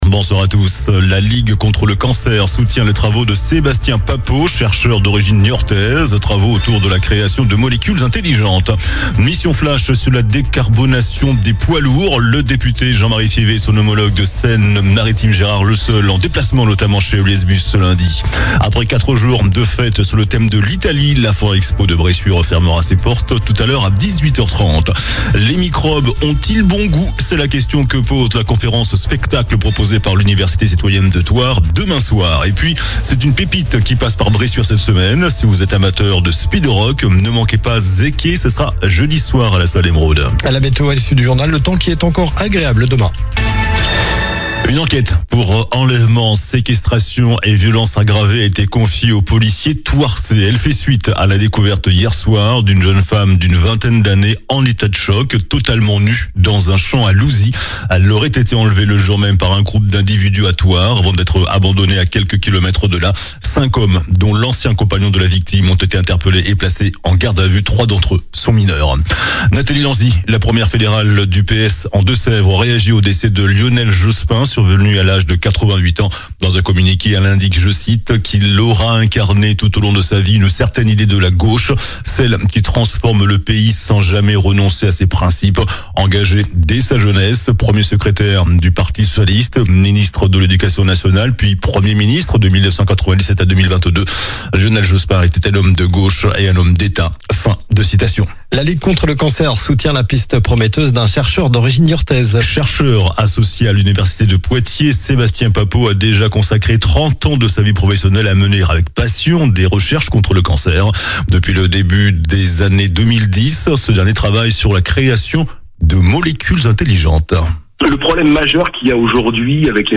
JOURNAL DU LUNDI 23 MARD ( SOIR )